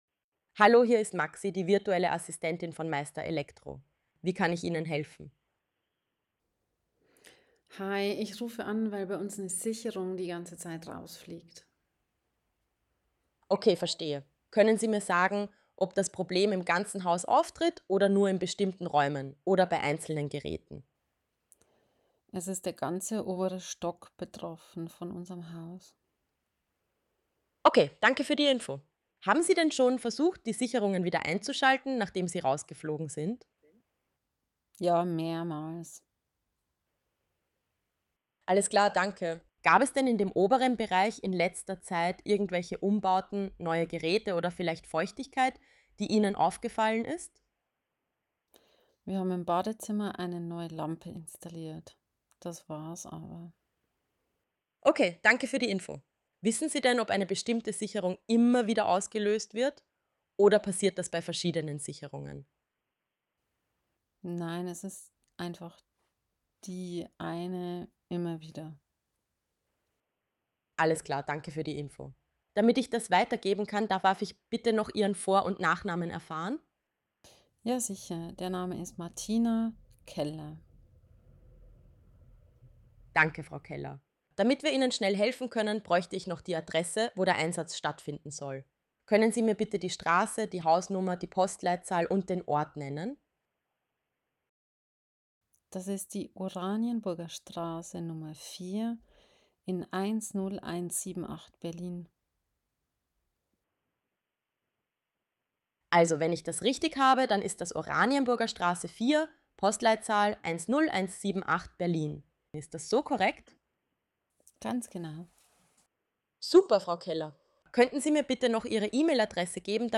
Beispielgespräch mit Frag Maxi